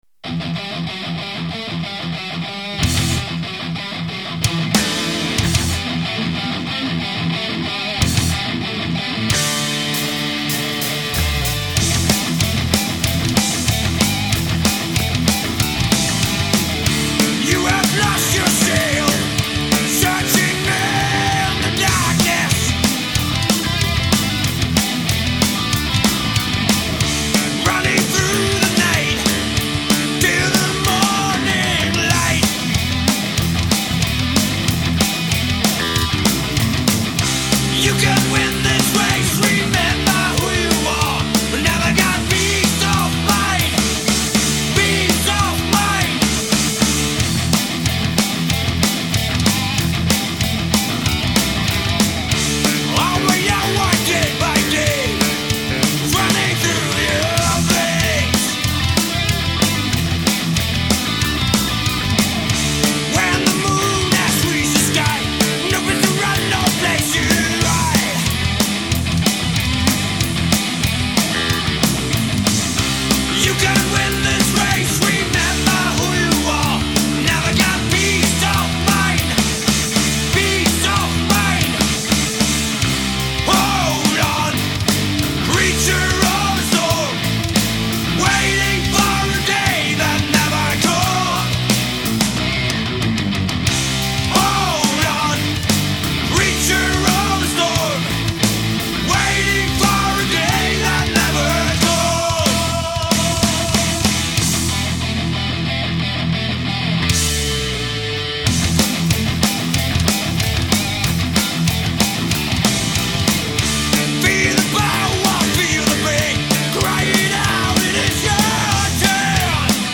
Stil : Power Metal